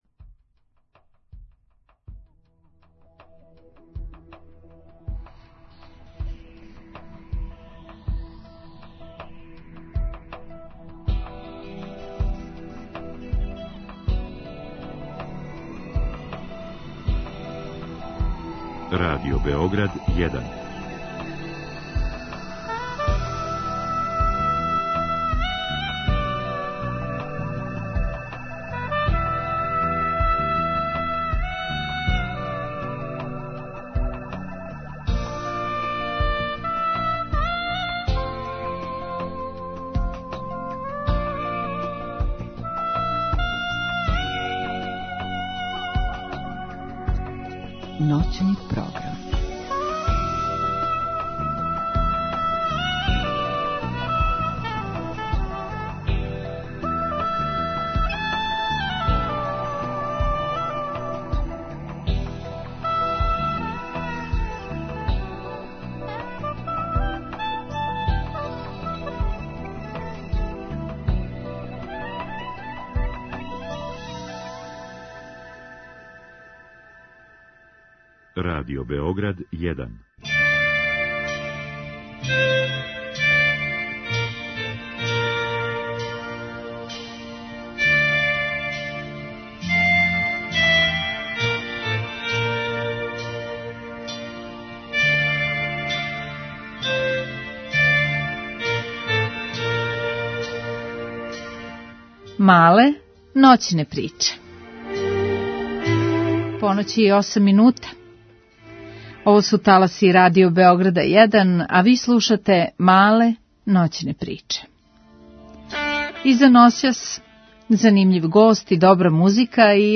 Група аутора Сваке ноћи, од поноћи до четири ујутру, са слушаоцима ће бити водитељи и гости у студију, а из ноћи у ноћ разликоваће се и концепт програма, тако да ће слушаоци моћи да изаберу ноћ која највише одговара њиховом укусу, било да желе да слушају оперу или их интересује технологија.